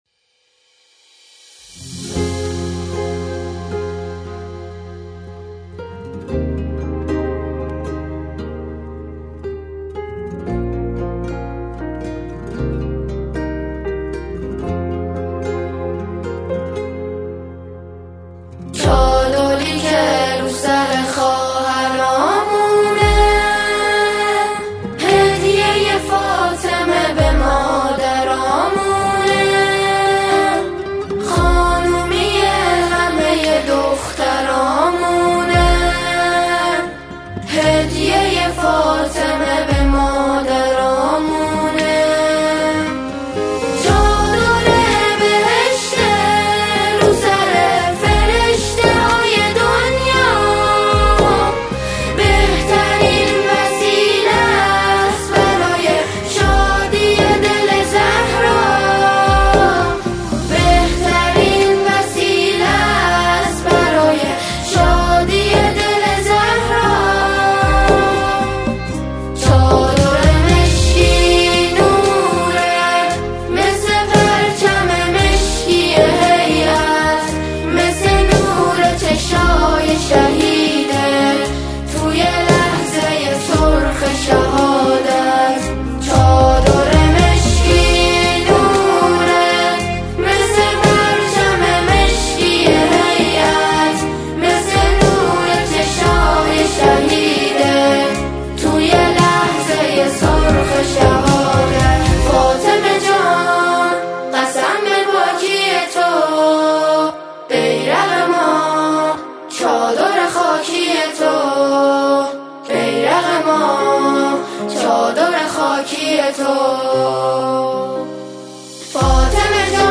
همخوانی فاطمیه سرود فاطمیه